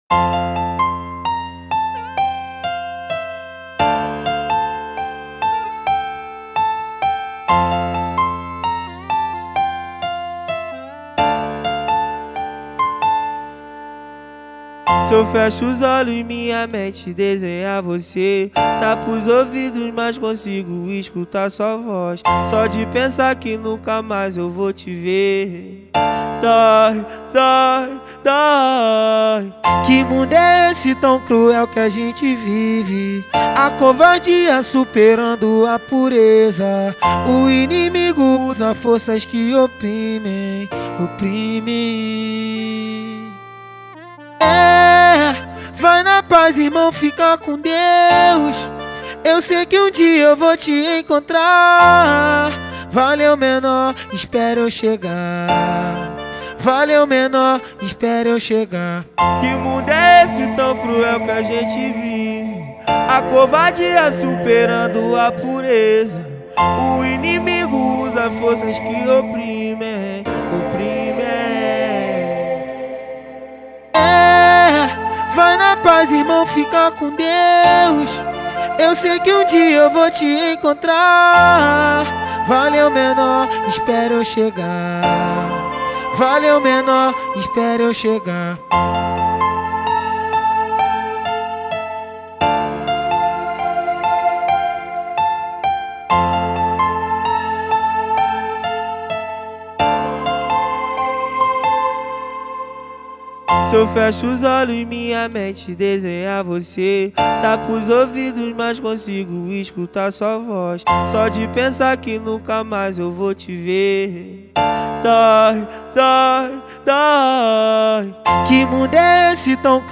2024-04-18 19:31:33 Gênero: Funk Views